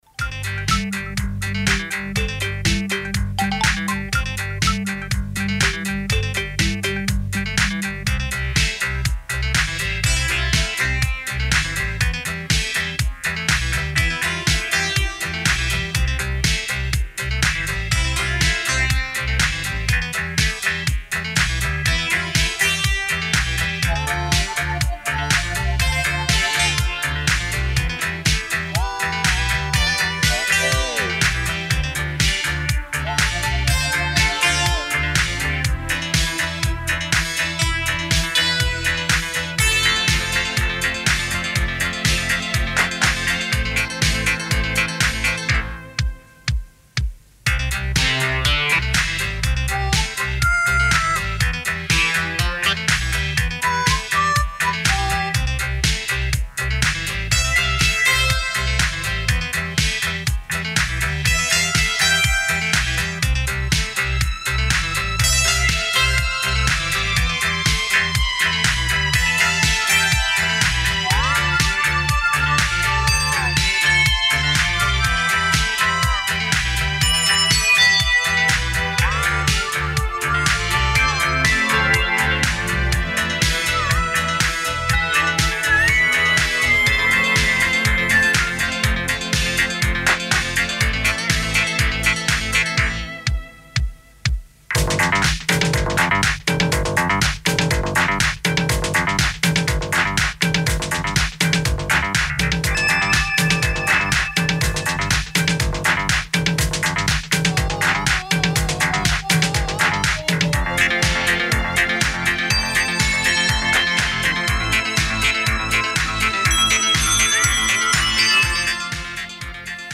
Этими музыкальными отрывками заполняли паузы между юмористическими монологами в передачах Всесоюзного радио.
Композиция 2 звучала на Всесоюзном радио в передаче С улыбкой (запись 29.06.1987).